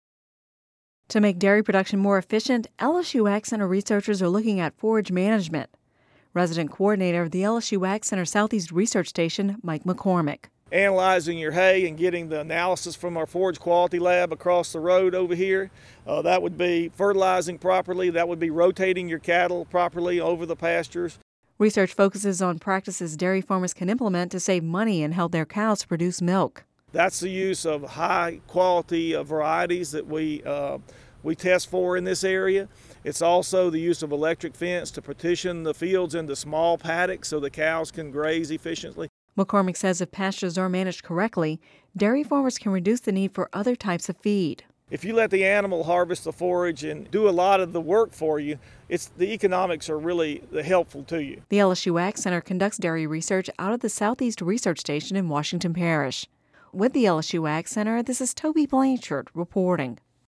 Description: (Radio News 12/27/10) To make dairy production more efficient, LSU AgCenter researchers are looking at forage management.